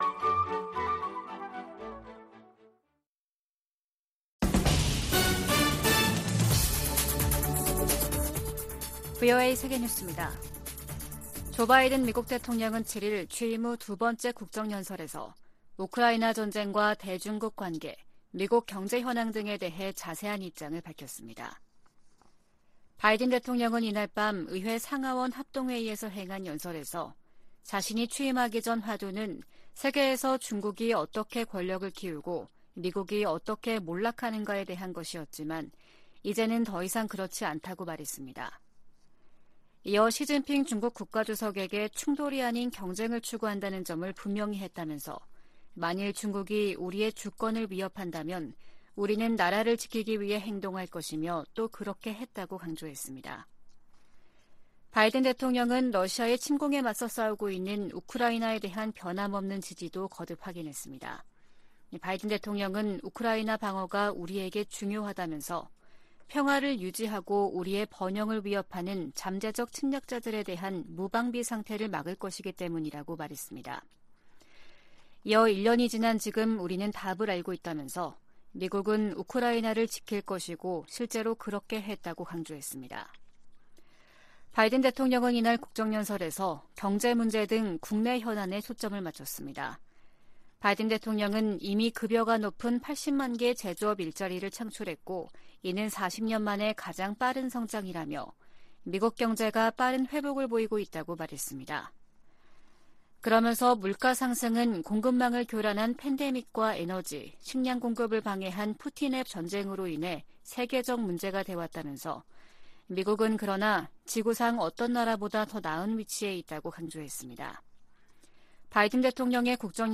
VOA 한국어 아침 뉴스 프로그램 '워싱턴 뉴스 광장' 2023년 2월 9일 방송입니다. 조 바이든 미국 대통령은 2일 국정연설을 통해 중국이 미국의 주권을 위협한다면 ‘우리는 나라를 보호하기 위해 행동할 것’이라고 말했습니다. 유엔이 국제적 긴장을 고조시키는 북한의 핵 개발과 미사일 발사에 우려한다는 입장을 거듭 확인했습니다.